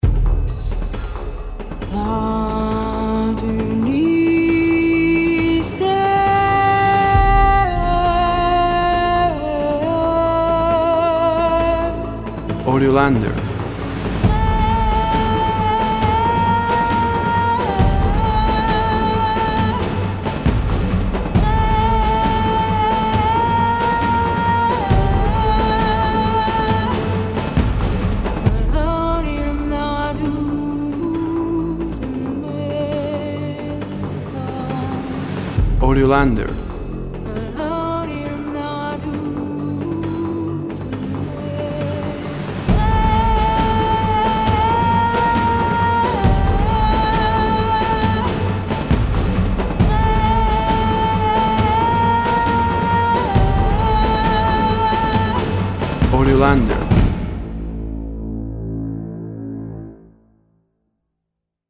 Fourth version, Ethnic voice and drum. Sinth Fx & Brass.
WAV Sample Rate 16-Bit Stereo, 44.1 kHz
Tempo (BPM) 127